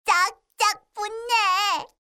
Kiss_Voice.mp3